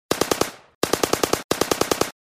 Звуки автомата Калашникова
Звук стрельбы из АК-74